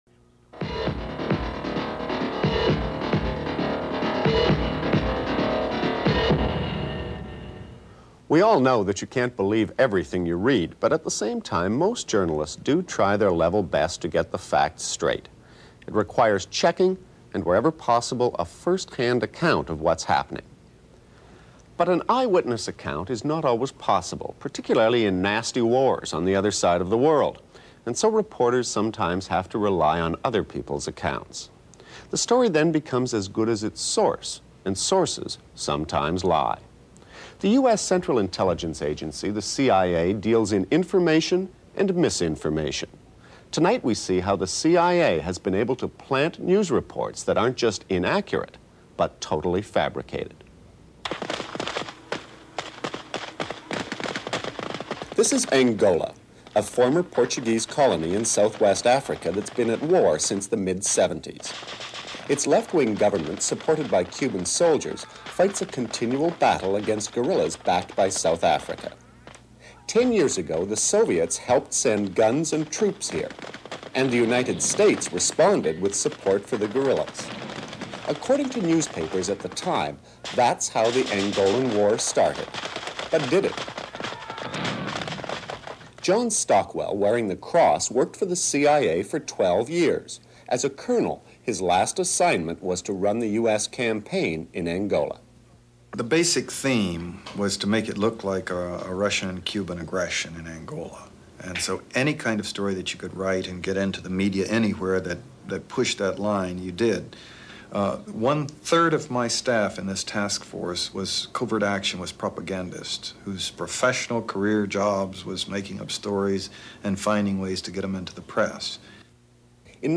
From 1986 Fifth Estate host Eric Malling investigates the covert falsification of news reports by CIA propagandists attempting to influence public perception of the world.